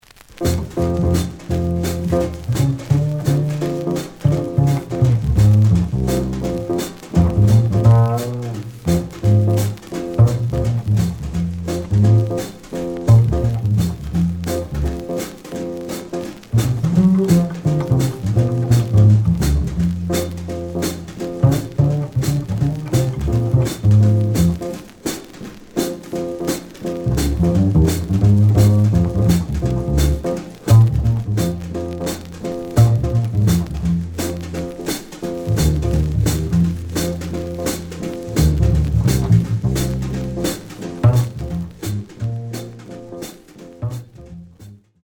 The audio sample is recorded from the actual item.
●Genre: Jazz Funk / Soul Jazz
Slight noise on both sides.